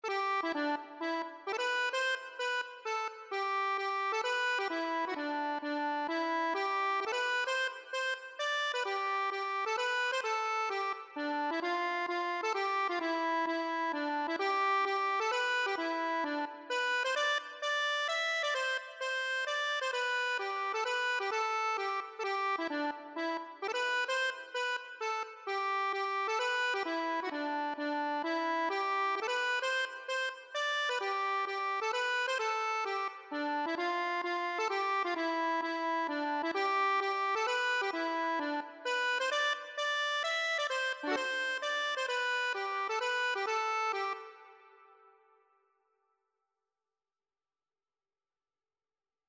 G major (Sounding Pitch) (View more G major Music for Accordion )
3/4 (View more 3/4 Music)
Easy Level: Recommended for Beginners with some playing experience
Accordion  (View more Easy Accordion Music)
Traditional (View more Traditional Accordion Music)